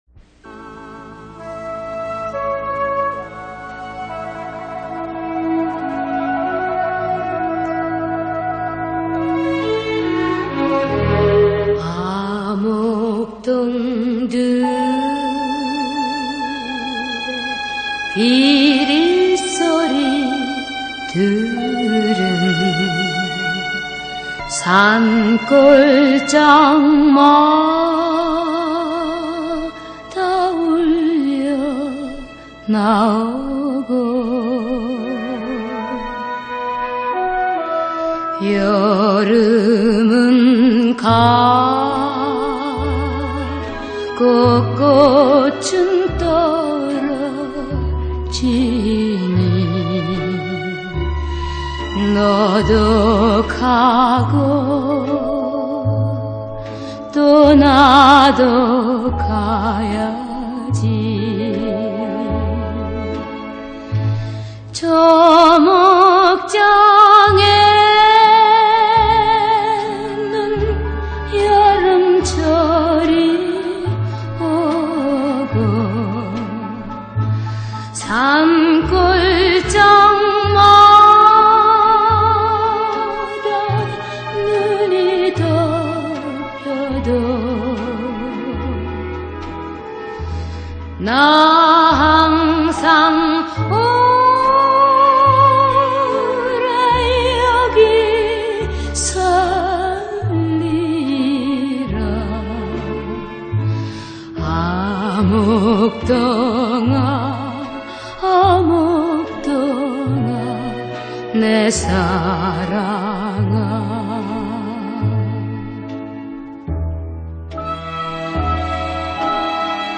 Ireland 民謠-